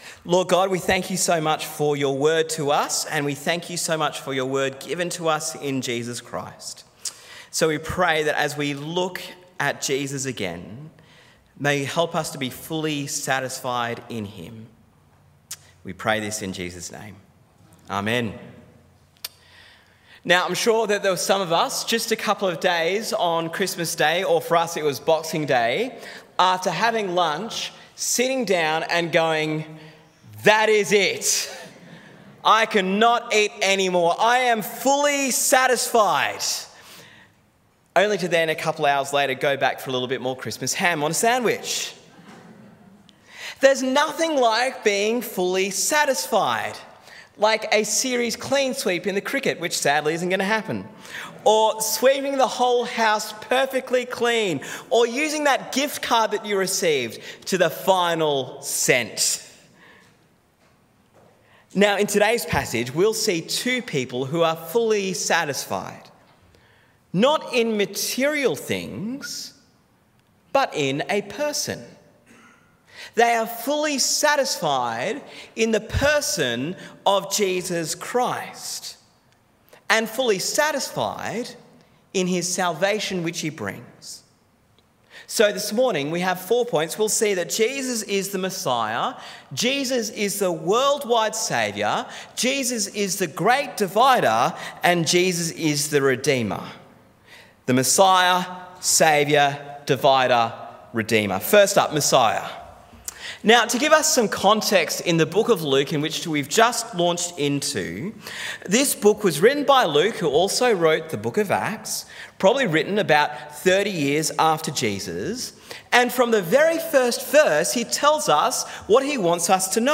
Listen to the sermon on Luke 2:22-28 in our The Incarnation series.